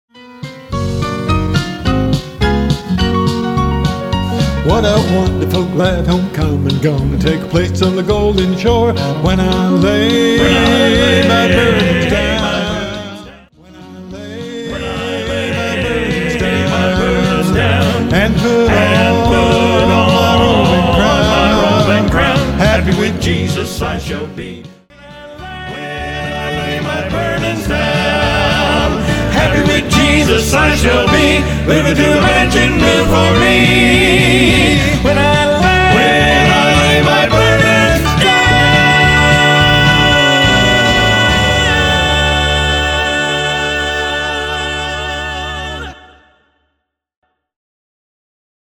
A Southern Gospel Music Ministry of Hope and Salvation